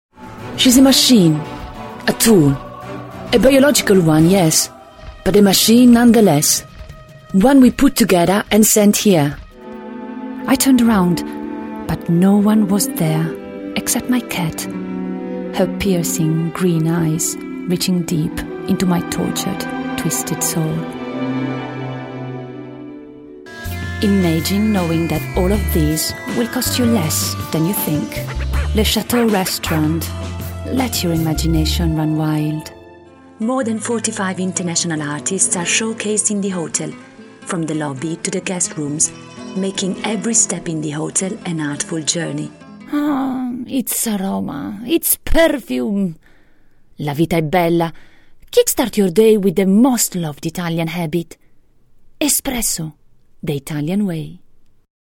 Voce fresca, elegante, chiara, sofisticata, sexy, civettuola
Sprechprobe: Werbung (Muttersprache):